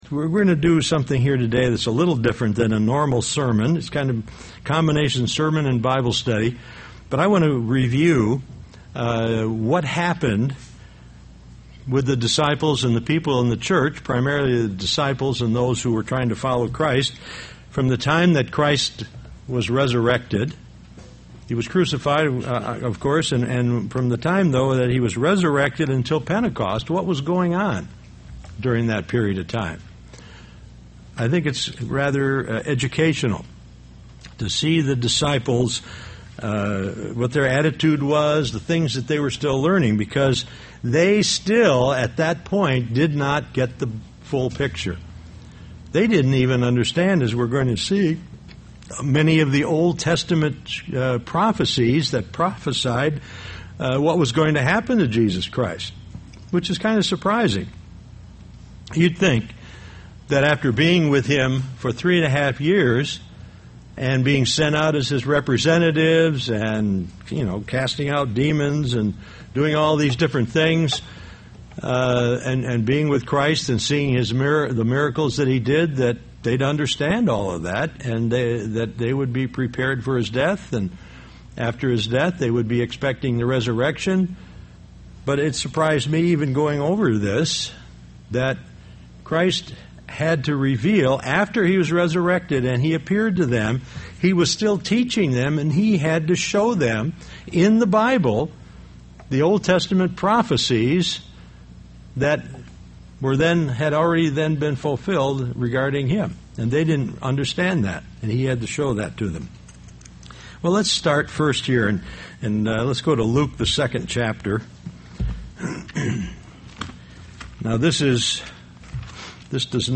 Given in Beloit, WI
UCG Sermon Studying the bible?